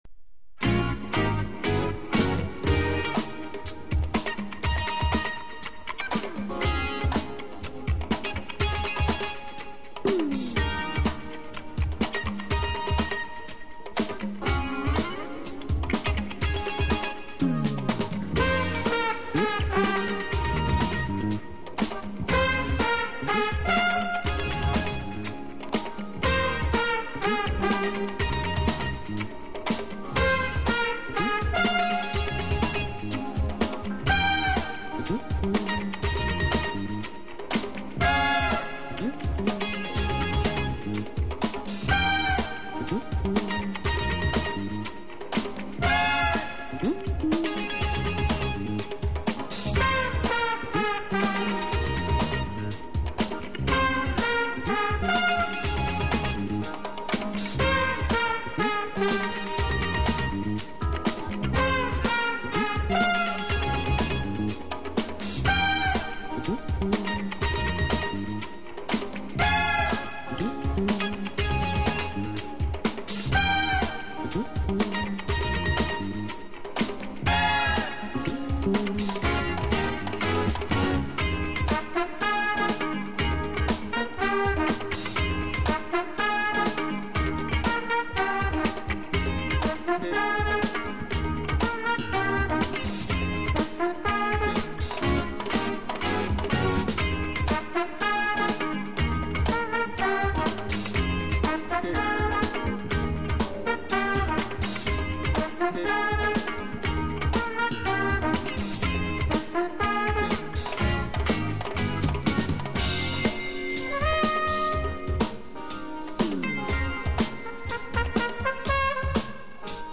（プレス・小傷によりチリ、プチ音ある曲あり）※曲名をクリックすると試聴できます。